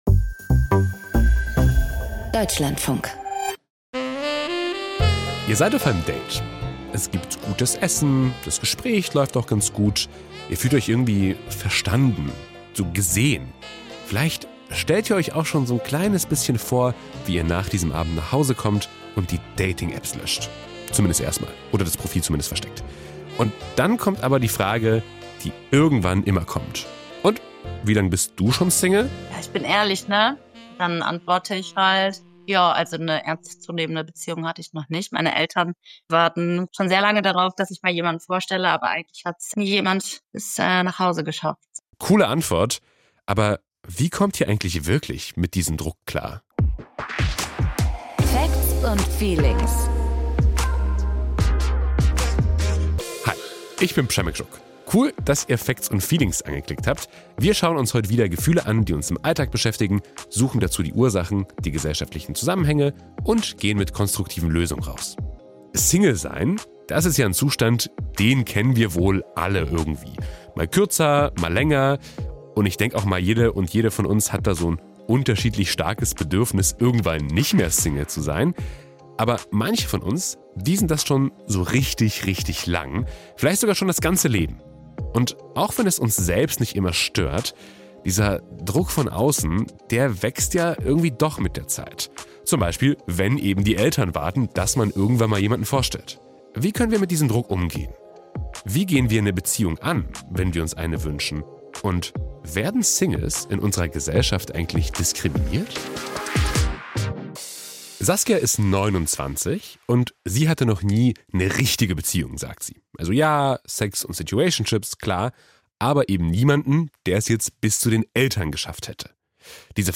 Die Gesellschaft diskriminiert Singles oft, sagt ein Soziologe. Was zu tun ist, wenn Druck von außen kommt, erklärt eine Therapeutin....